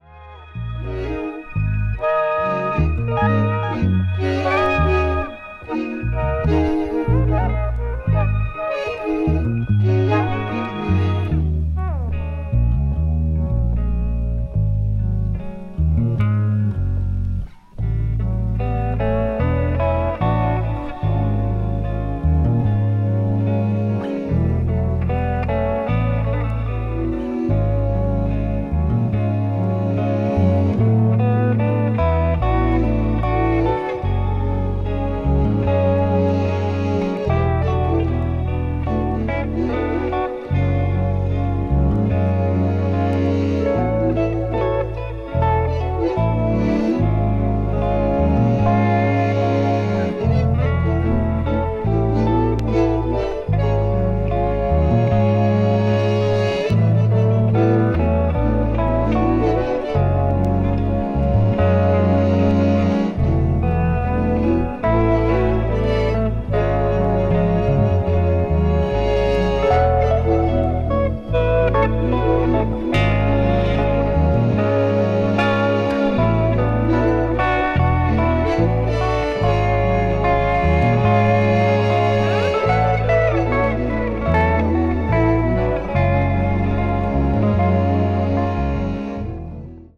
本作は、ヴァンナイズにある伝説的レコーディング・スタジオ＜Sound City Studios＞で録音された。